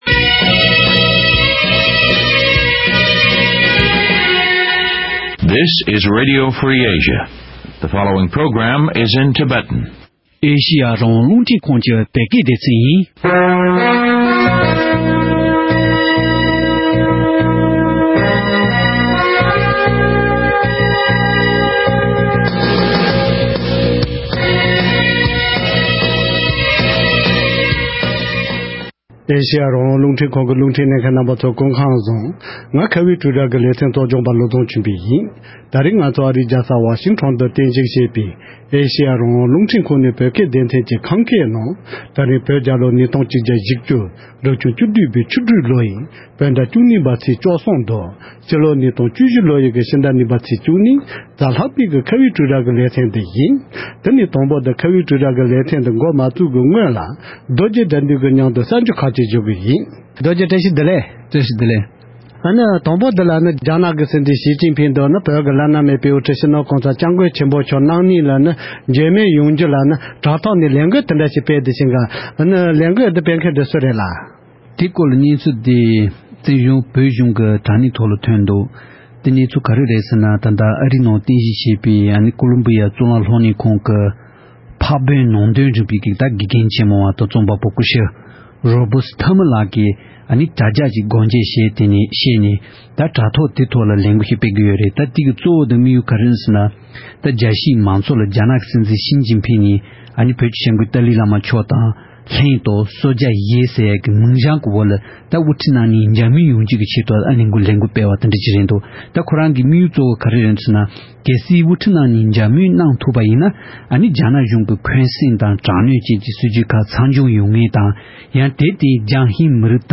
༄༅། །ཐེངས་འདིའི་ཁ་བའི་གྲོས་རྭ་ཞེས་པའི་ལེ་ཚན་འདིའི་ནང་། རྒྱ་གར་གྱི་རྡོ་རྗེ་གདན་དུ་རྙིང་མ་སྨོན་ལམ་ཆེན་མོ་དབུ་འཛུགས་གནང་ནས་ལོ་ངོ་༢༥འཁོར་ཡོད་པ་དང་། རྙིང་མའི་སྨོན་ལམ་ཆེན་མོ་ཞེས་པ་དེ་ཐོག་མ་དབུ་འཛུགས་གནང་སྟངས་སོགས་ཀྱི་སྐོར་ལ་གླེང་མོལ་ཞུས་པ་ཞིག་གསན་རོགས་གནང་།།